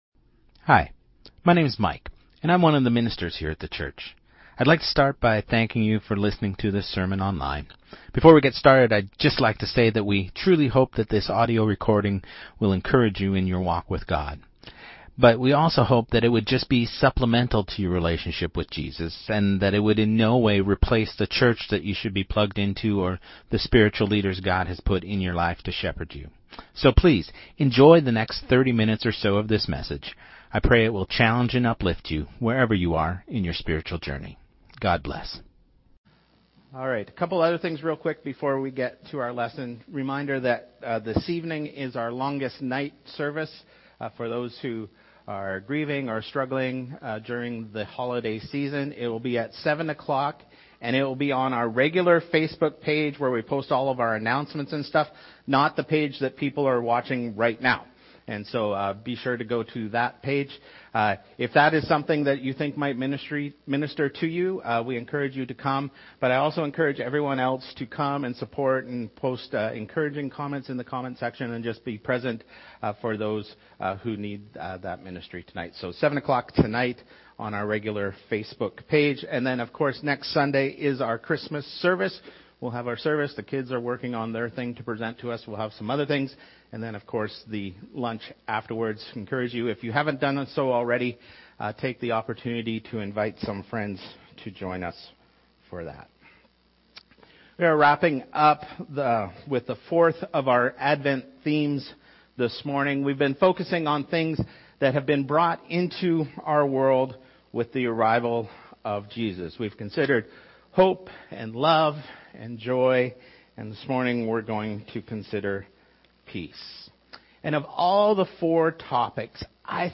Sermon2025-12-14